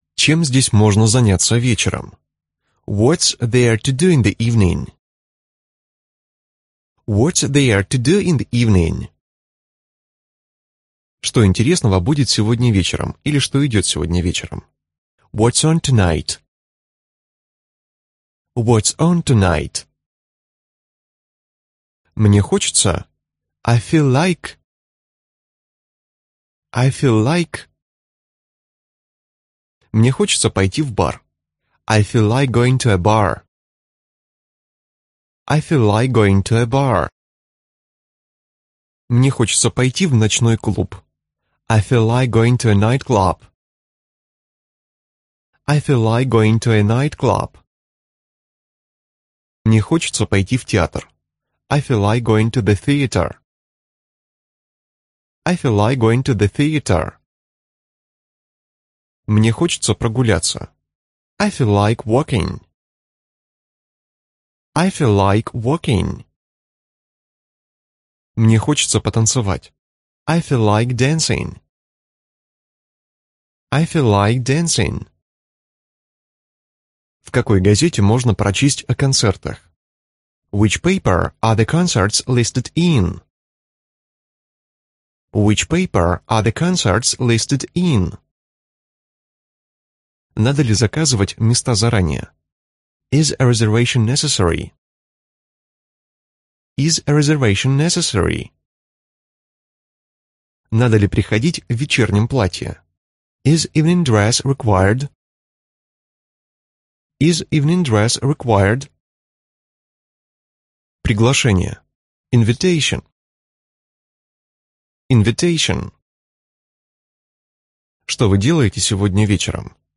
Аудиокнига Английский для туристов | Библиотека аудиокниг